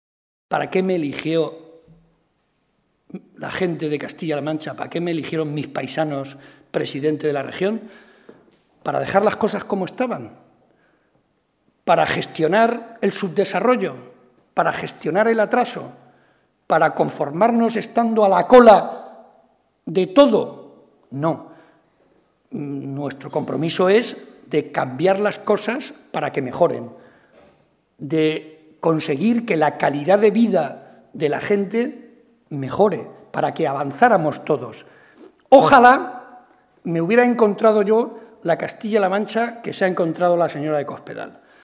“De la misma manera que en el fútbol hasta el minuto final se puede dar la vuelta al partido, también se le puede dar la vuelta a las encuestas y a los pronósticos” ha asegurado el cabeza de lista del PSOE al Congreso, José María Barreda, durante su intervención en un acto público en la localidad de Miguelturra.
Cortes de audio de la rueda de prensa